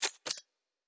pgs/Assets/Audio/Sci-Fi Sounds/Weapons/Weapon 04 Reload 2.wav at master
Weapon 04 Reload 2.wav